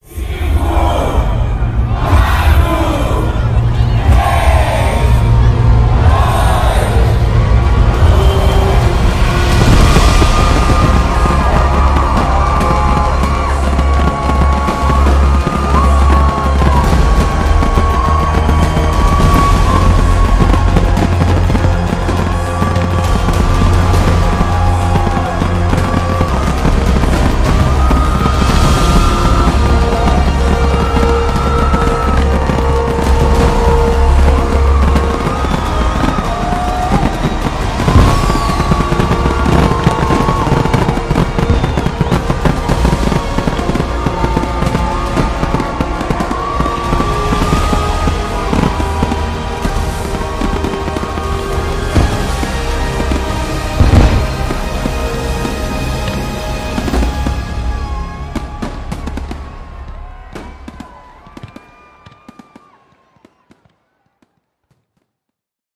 Sinta-o-clima-da-chegada-do-Ano-Novo-em-Copacabana.mp3